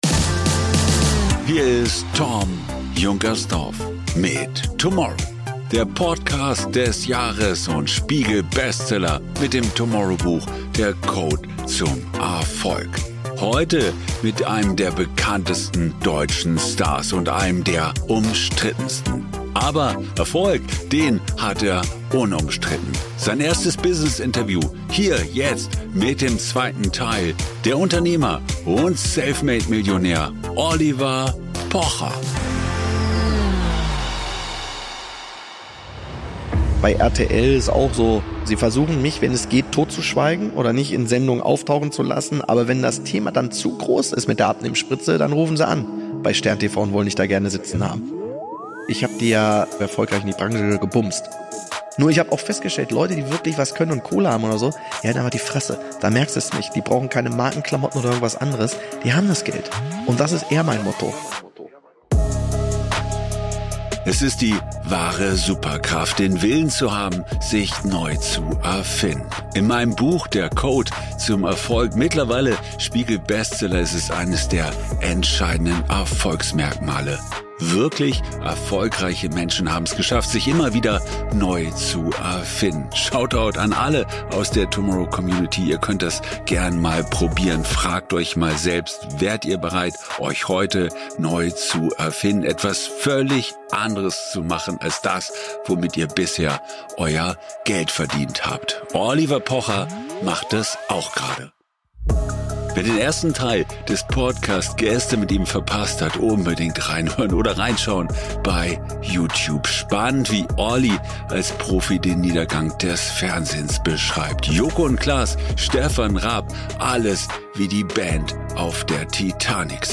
In seinem ersten großen Business-Interview spricht Oli Pocher darüber, wie er Chancen erkennt und daraus sofort ein Geschäftsmodell baut.
Hier kannst du Oli live im Roomers Hotel München sehen.